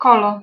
Ääntäminen
Synonyymit hourque cormet cluse Ääntäminen France: IPA: [ɛ̃ kɔl] Tuntematon aksentti: IPA: /kɔl/ Haettu sana löytyi näillä lähdekielillä: ranska Käännös Ääninäyte Substantiivit 1. kolumo 2. montpasejo 3. kolo Suku: m .